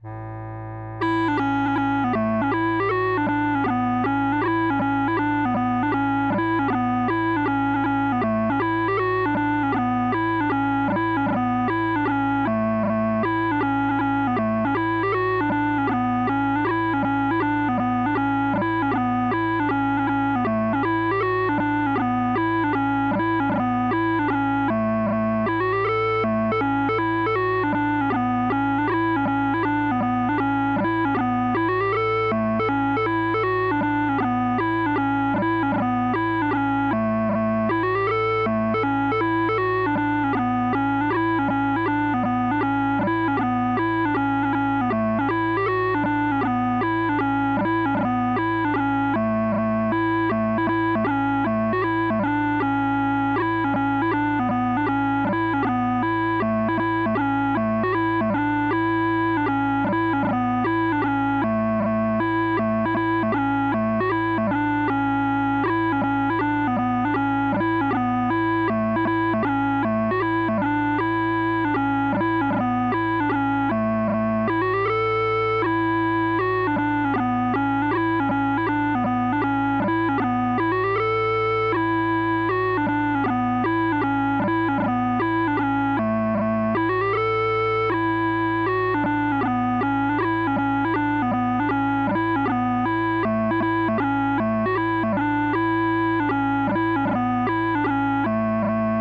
PIPES: All files ZIP / Pipes.pdf / Mp3 40bpm / Mp3 60bpm /
Mp3 80bpm